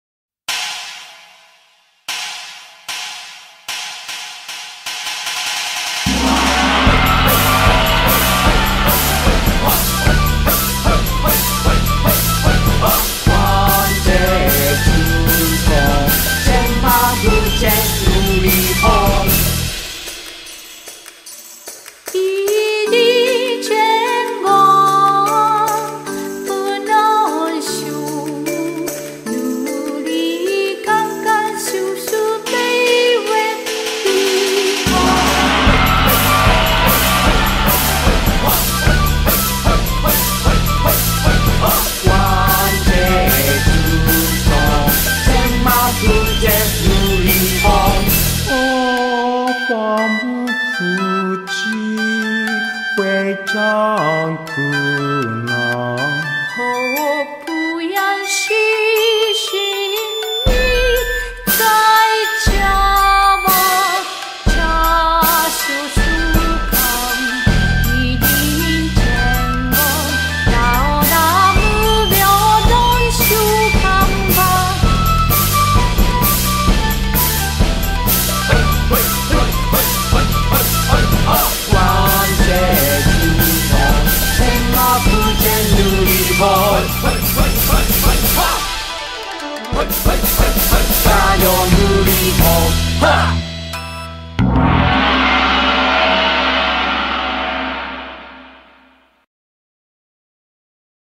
BPM75-180
Audio QualityPerfect (Low Quality)